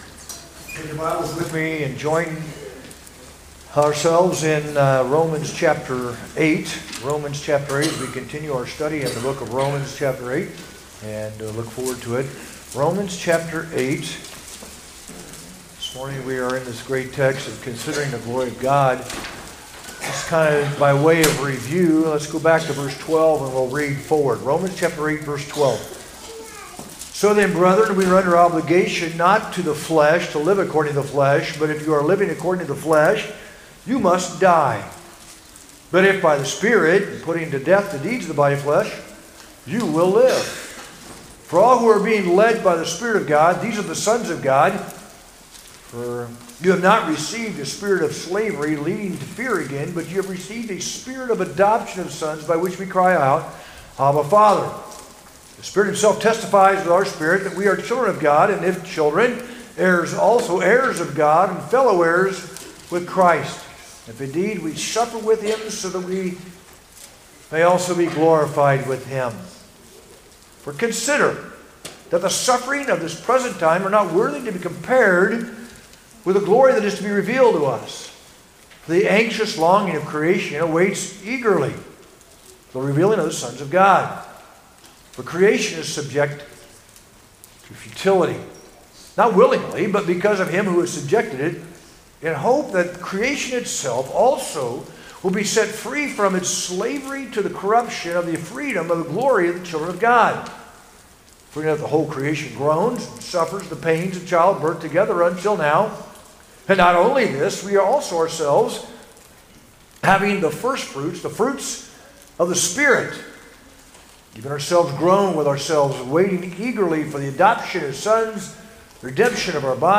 sermon-12-8-24.mp3